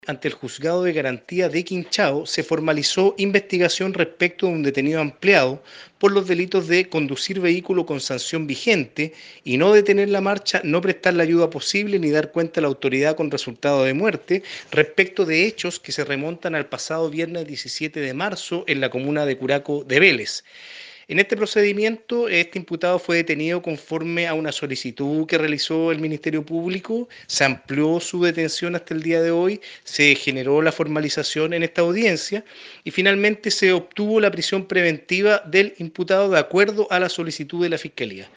De esta manera lo dio a conocer el fiscal Cristian Mena.